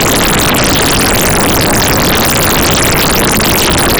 Interesting audio, and band limited, but not quite noise, so here is the same thing, switching frequency every 40 samples instead of every 4000 samples.
It is technically noise, and it is band limited, but it sounds weird.  Like a tape player on fast forward or water flowing quickly or something.
noisebl.wav